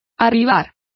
Complete with pronunciation of the translation of arrive.